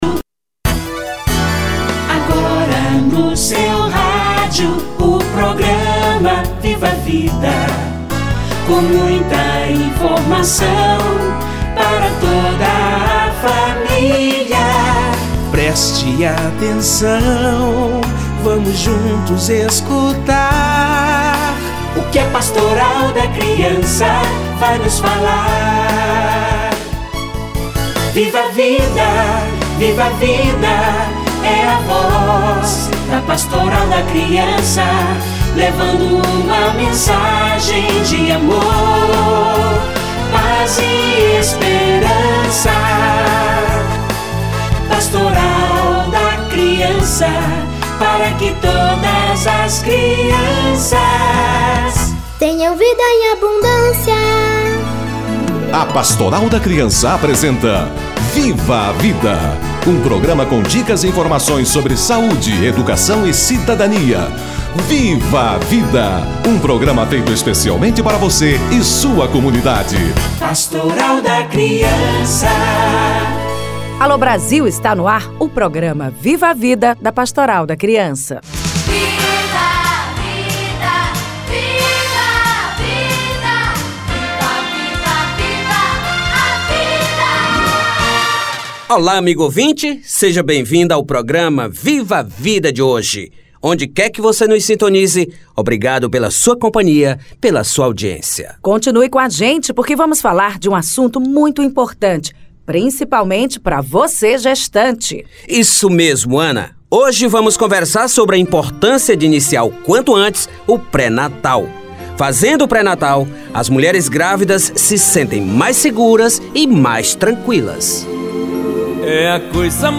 Mutirao em busca das gestantes - Entrevista